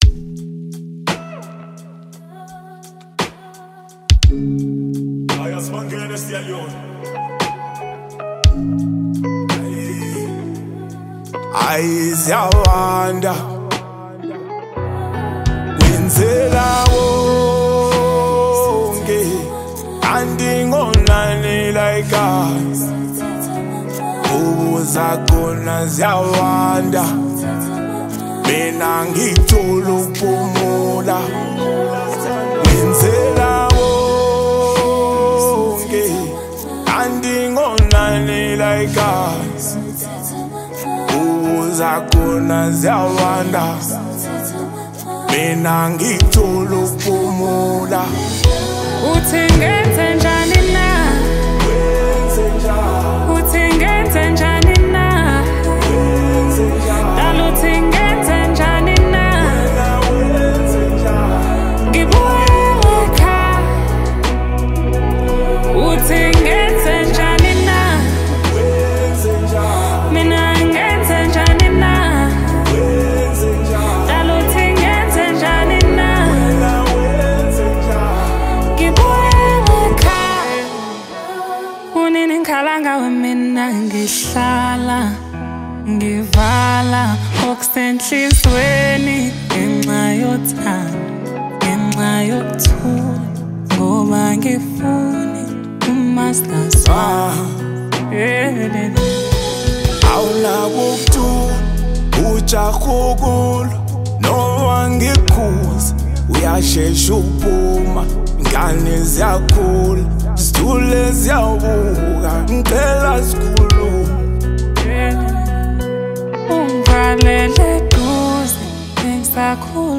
Award-winning South African musician
new love song
perfect vocals